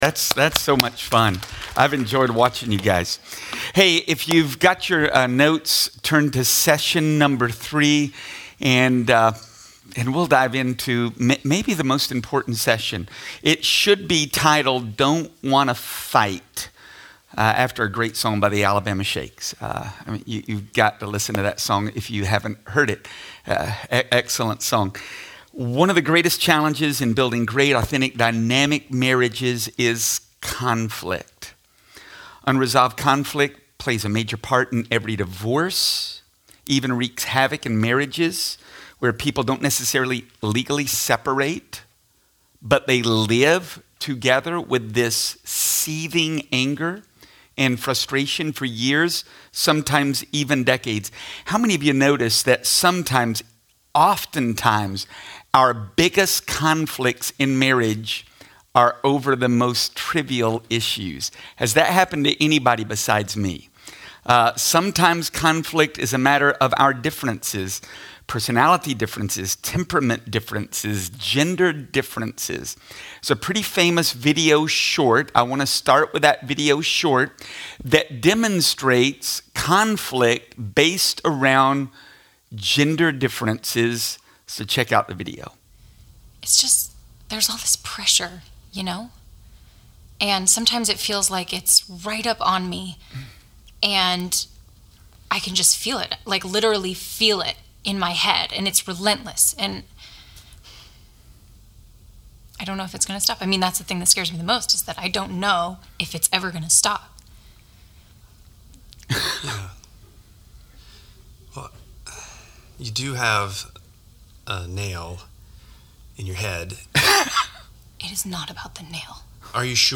STAYING IN LOVE MARRIAGE CONFERENCE